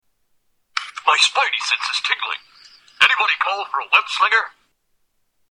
Stacy Doll with unexpected voice box
Category: Television   Right: Personal